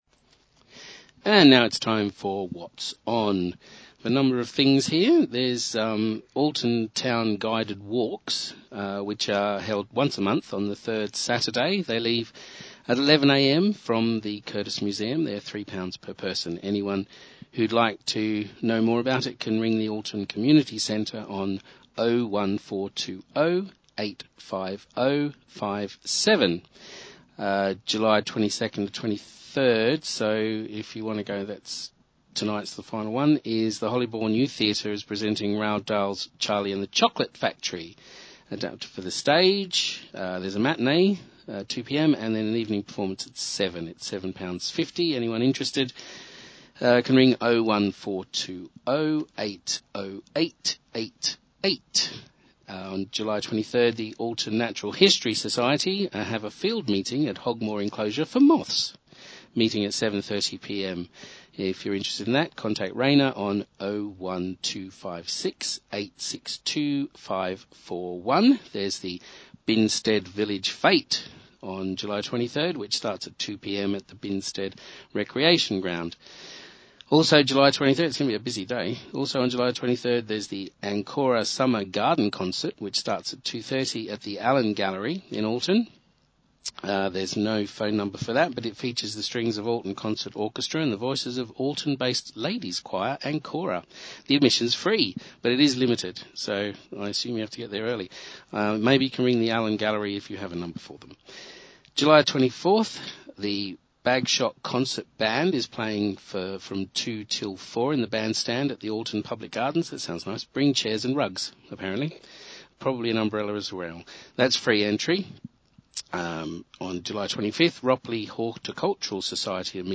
We went into the studio and read and all was fine.